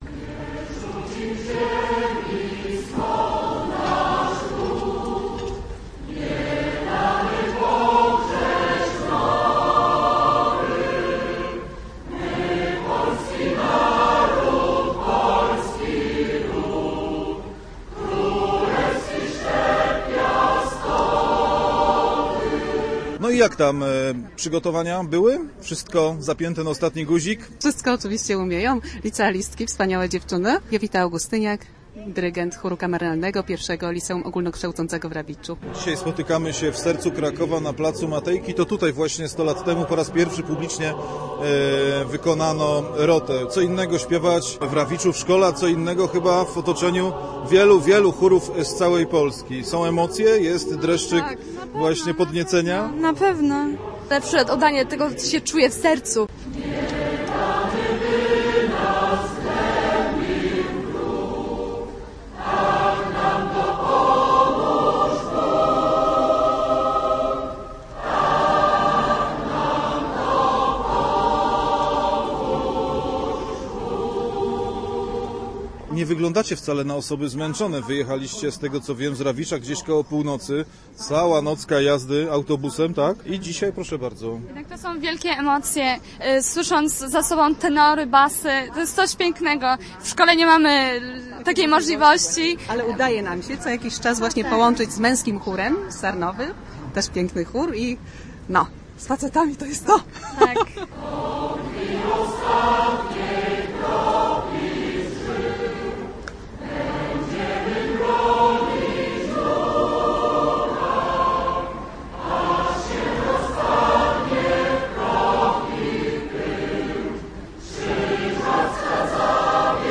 60 chórów zaśpiewało Rotę
Kraków świętował w sobotę setną rocznicę pierwszego wykonania Roty. Na plac Matejki, na którym w 1910 roku po raz pierwszy zaśpiewano ten patriotyczny utwór, przyjechało ponad 60 chórów z całego kraju.